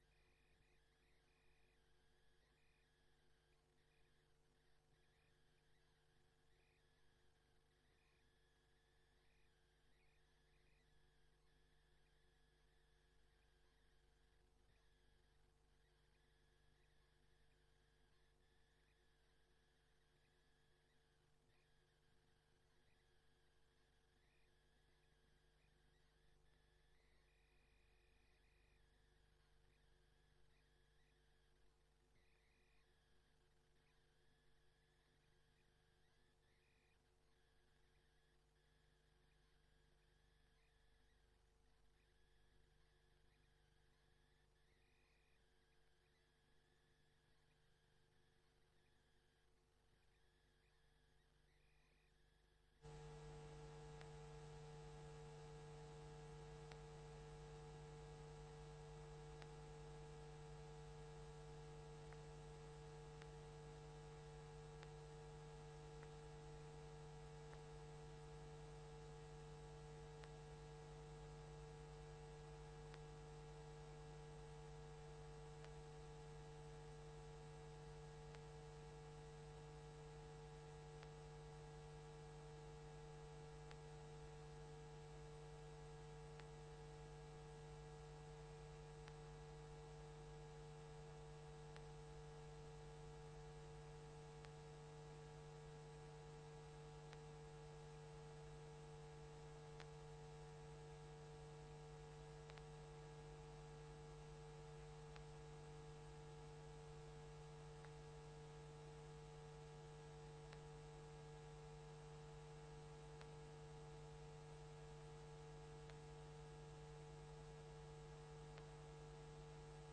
Bijzondere raadsvergadering
Locatie: Raadzaal